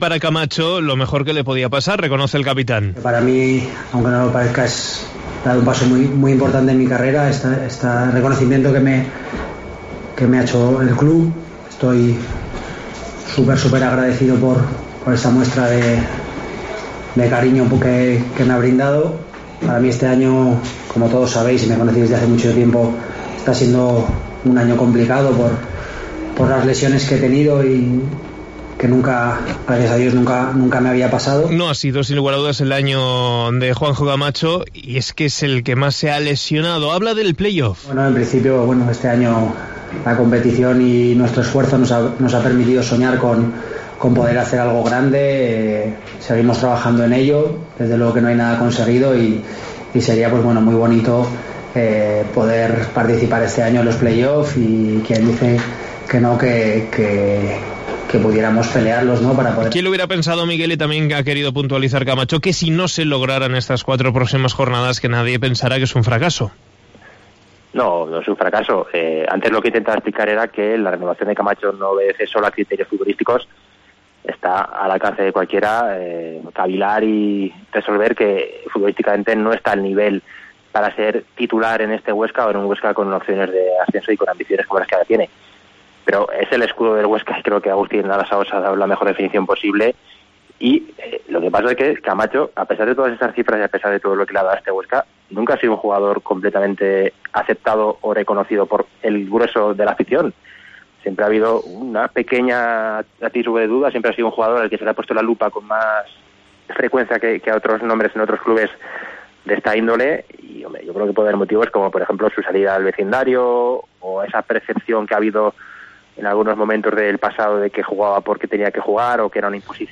en sala de prensa